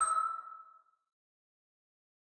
Index of /cstrike/sound/turret
tu_ping.wav